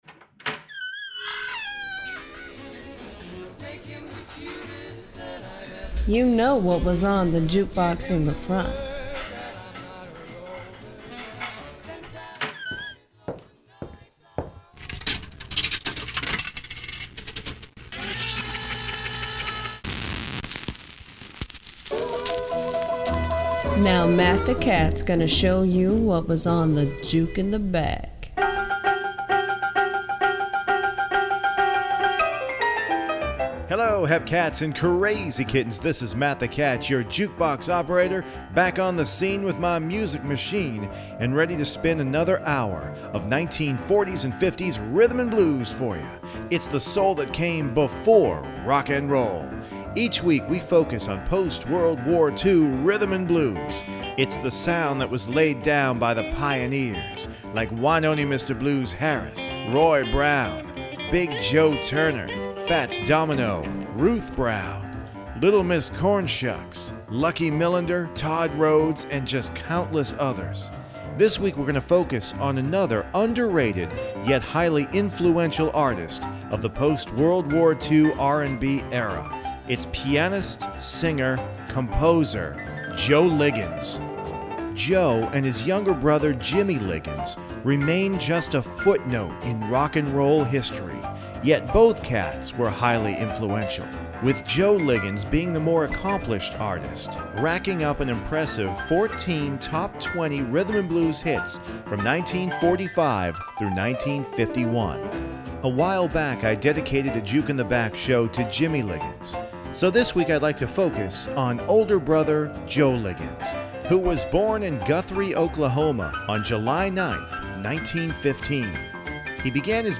Singer/songwriter and music historian, Billy Vera drops by the “Juke” to tell us how “The Honeydripper” became such an enormous hit.